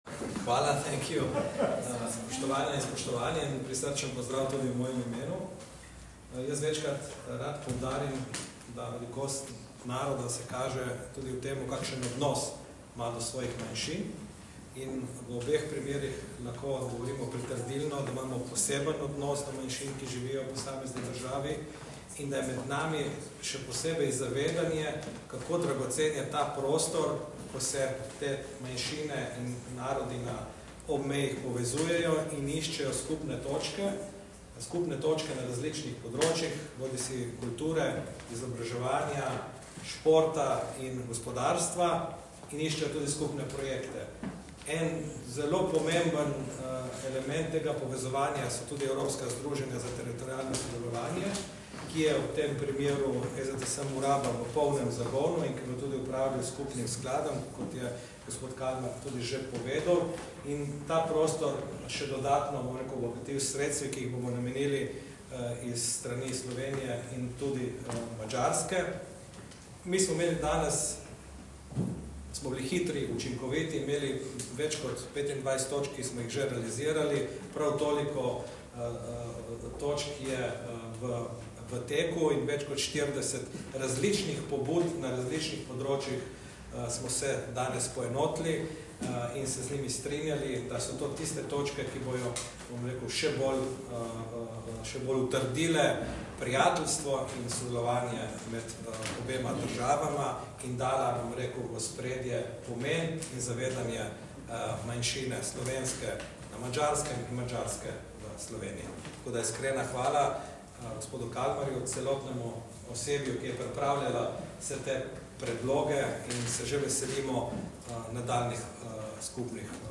Na povezavah dodajamo zvočna posnetka izjave za medije obeh sopredsedujočih ob zaključku zasedanja:
Izjava Matej Arčon